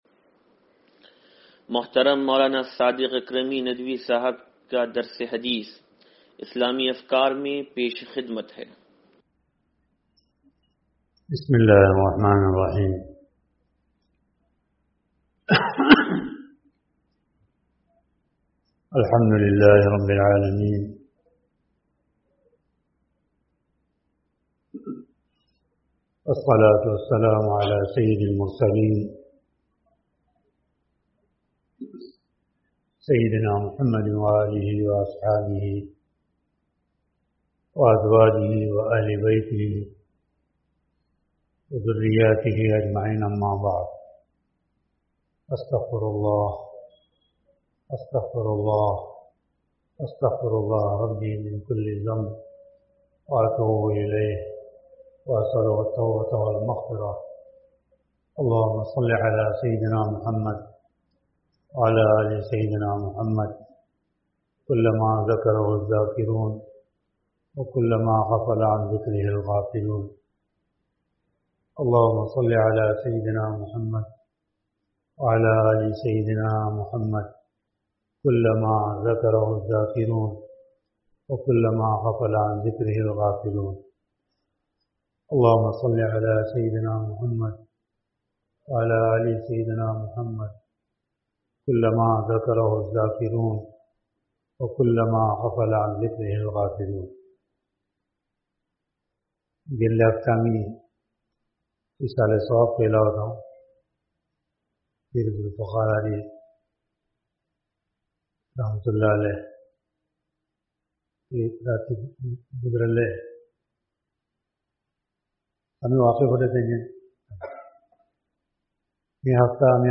درس حدیث نمبر 0628